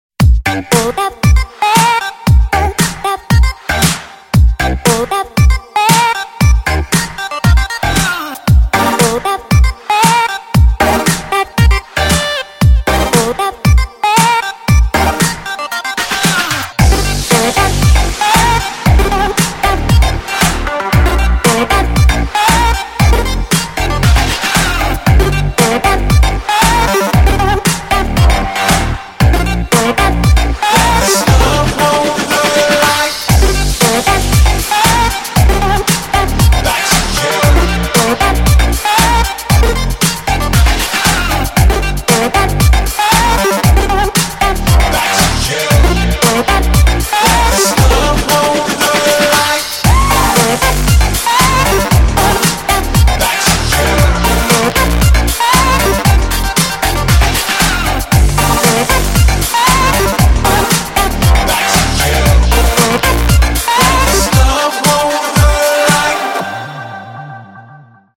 • Качество: 152, Stereo
dance
Electronic
Приятный и энергичный трек!